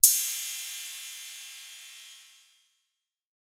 Crashes & Cymbals
Cym - Redd.wav